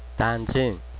岐阜県東濃地方の方言
東濃弁を聞いてみよう